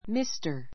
Mr. Mr 中 A1 místə r ミ ス タ 略語 ❶ ～氏 , ～さん , ～様, ～先生 ⦣ （成人した）男性への敬称. mister master .